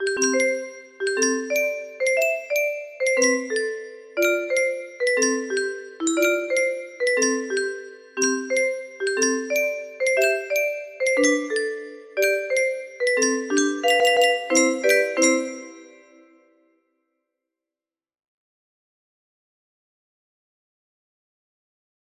30128 music box melody